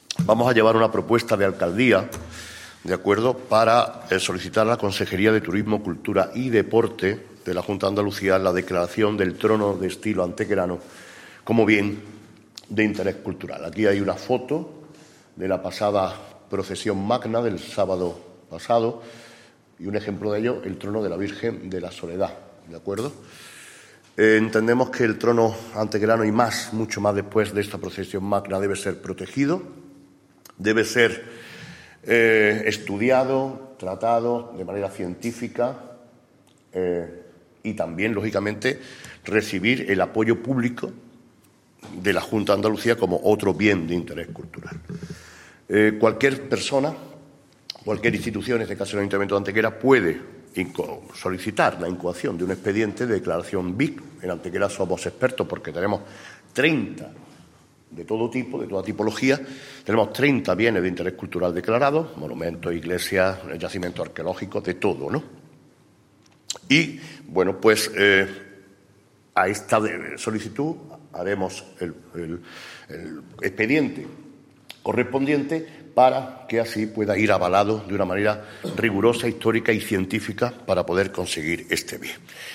El alcalde de Antequera, Manolo Barón, ha anunciado hoy en rueda de prensa que elevará al próximo Pleno Municipal del día 19 una propuesta de Alcaldía para solicitar a la Consejería de Turismo, Cultura y Deporte de la Junta de Andalucía la declaración del "Trono de Estilo Antequerano" como Bien de Interés Cultural de nuestra comunidad autónoma.
Cortes de voz